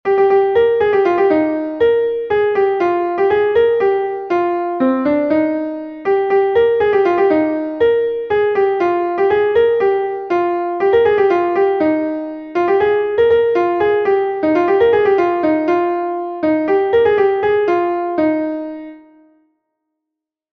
Ur Bloavezh Mat A Souhetañ is a Bale from Brittany